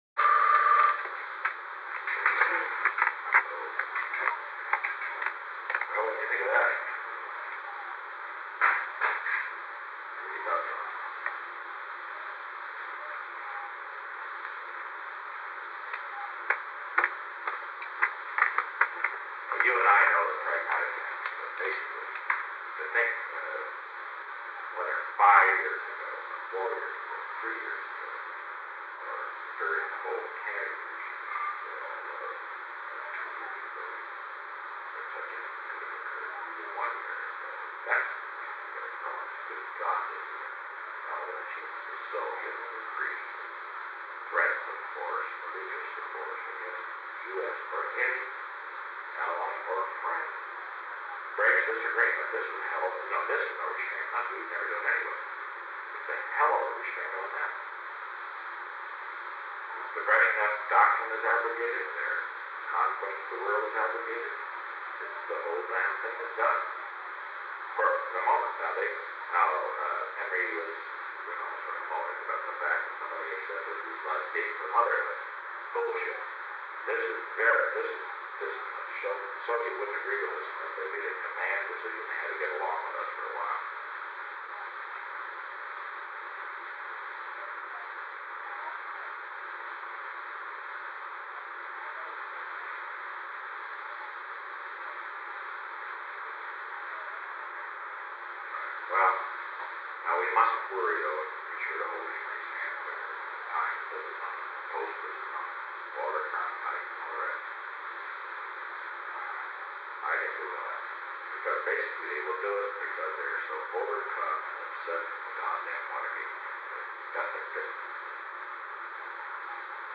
Secret White House Tapes
Conversation No. 448-7
Location: Executive Office Building
The President talked with Alexander M. Haig